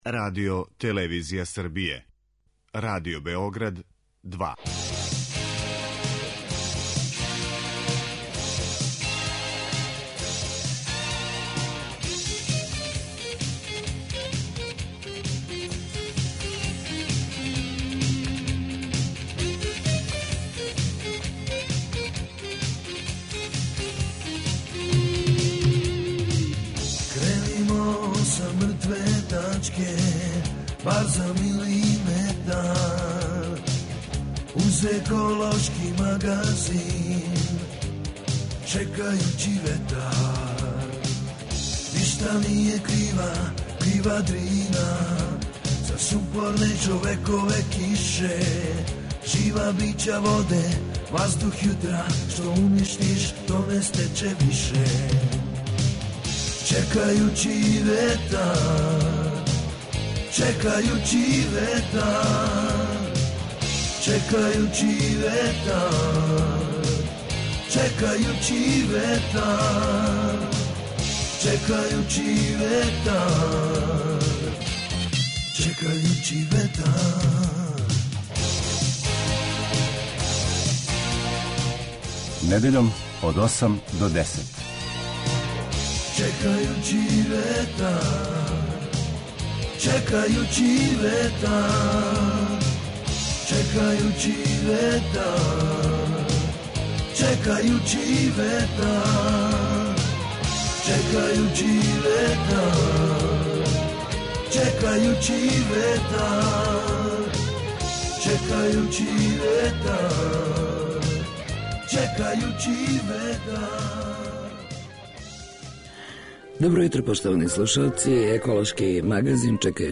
Еколошки магазин се ове недеље емитује директно из Бајине Баште